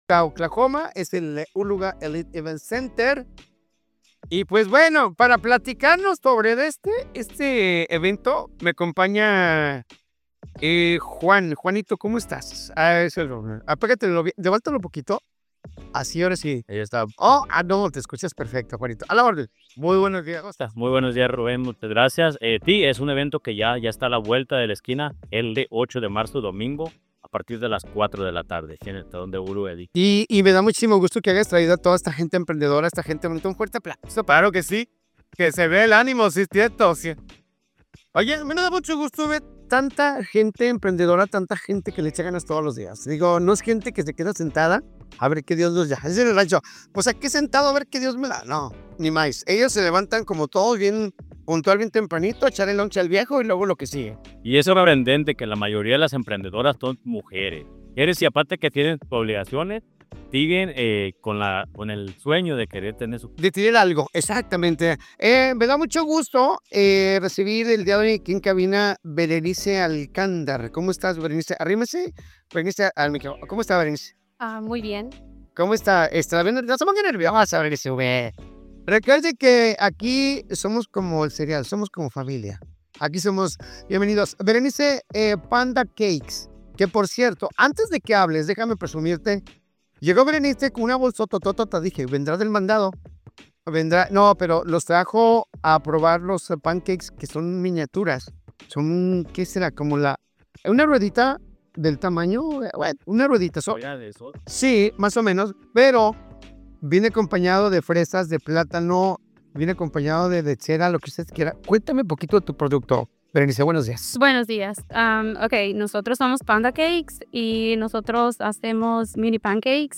Durante una entrevista transmitida hace unos minutos en Que Buena Tulsa, organizadores dieron a conocer los detalles de la Expo Bodas y Quinceañeras 2026, un evento que busca fortalecer el emprendimiento latino en la región.